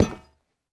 minecraft / sounds / block / vault / step4.ogg
step4.ogg